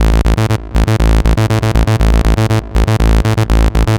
TSNRG2 Bassline 031.wav